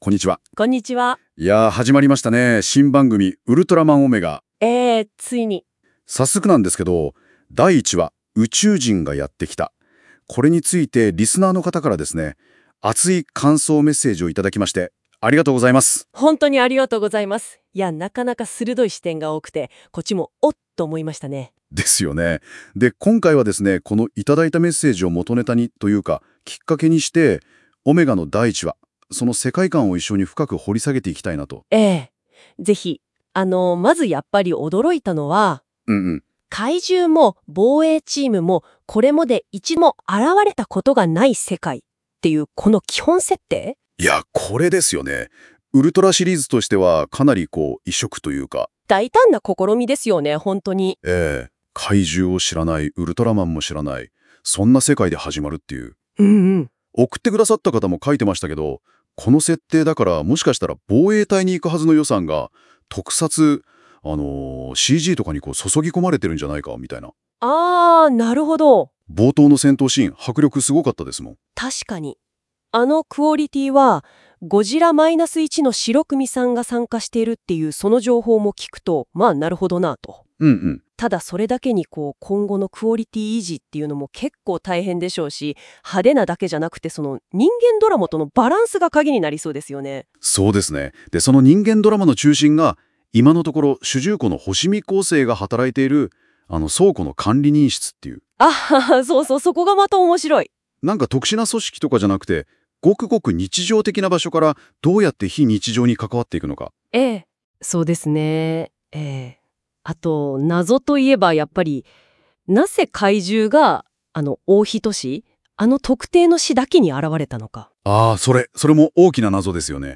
それにしてもAI、ちゃんと自分で漢字の原稿まで作っているのに、それを読み間違いして…
Audio Channels: 1 (mono)